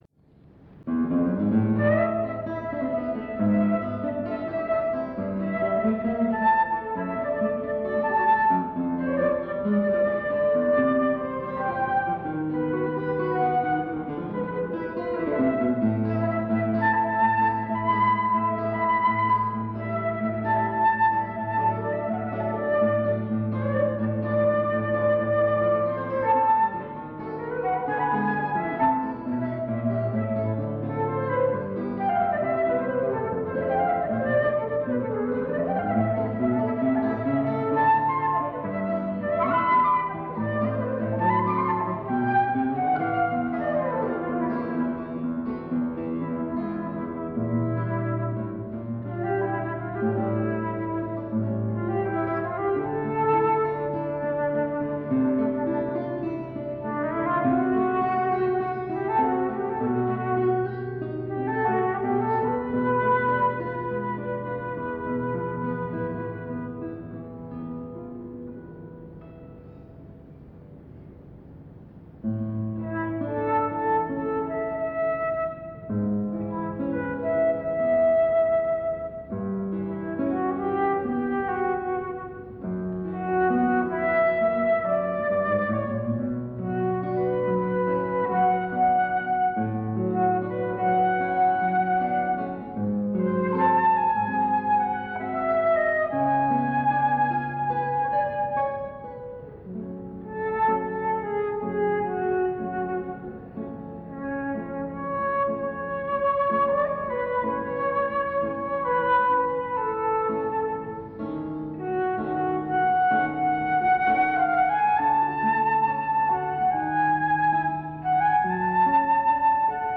Duo flauto e chitarra
Circolo Eridano, Torino 2 Aprile 1993